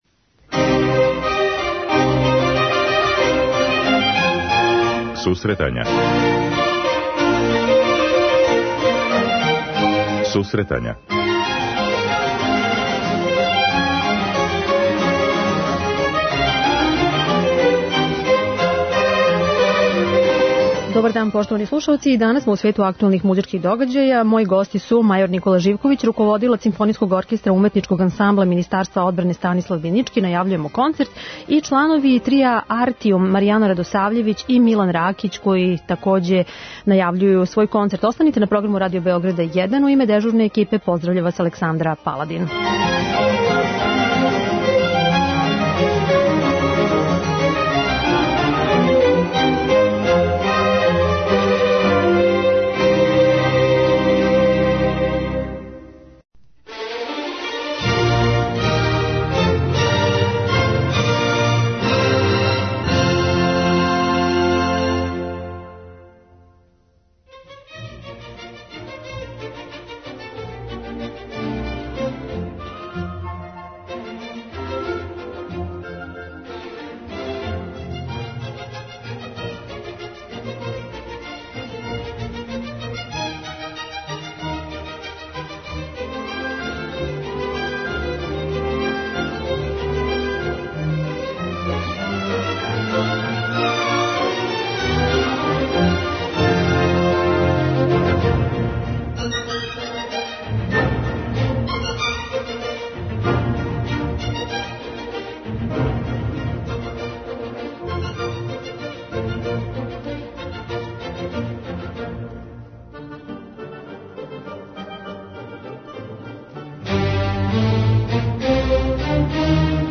преузми : 10.75 MB Сусретања Autor: Музичка редакција Емисија за оне који воле уметничку музику.